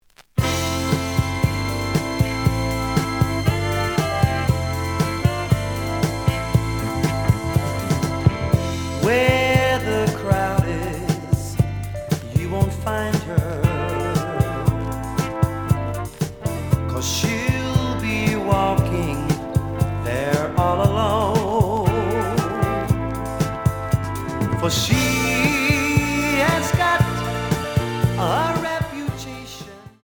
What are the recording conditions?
The audio sample is recorded from the actual item. Slight noise on beginning of B side, but almost good.)